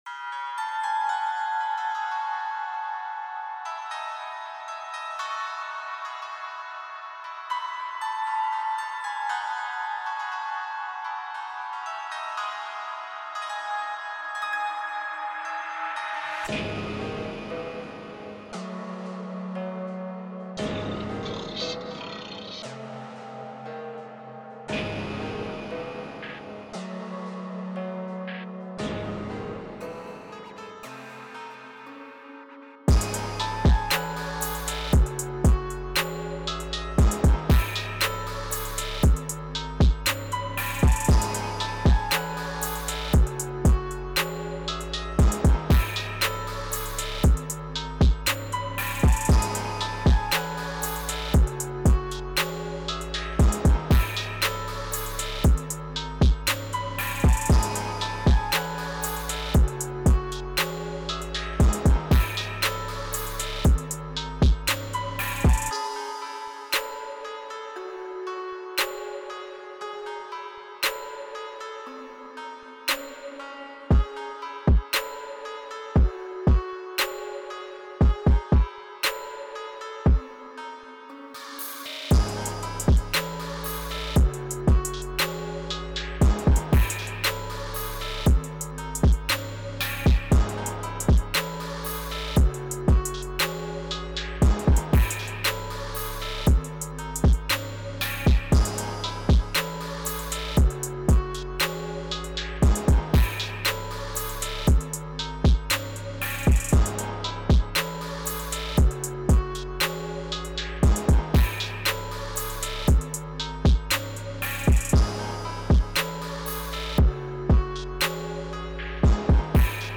7 Claps
7 FX
6 Open & 5 Closed Hats
8 Kicks
10 Percs
10 Snares
6 Vox